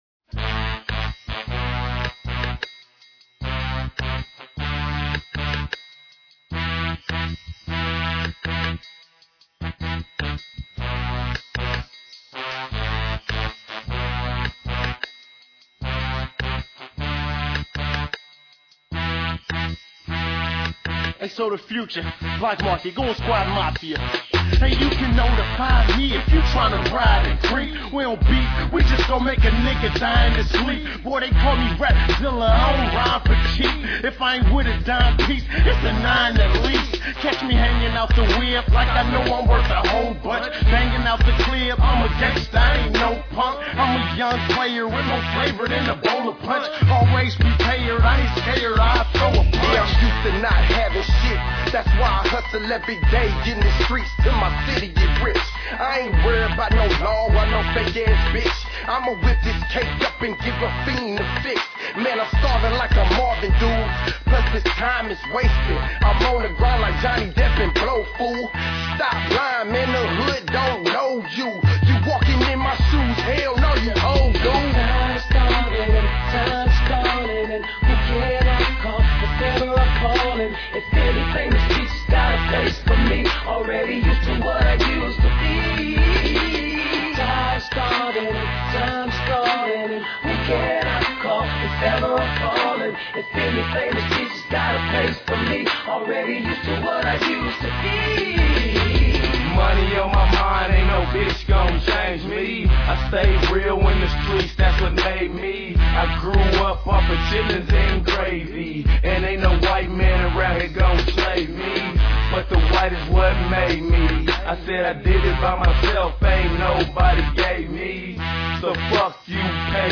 Tags: rap, mp3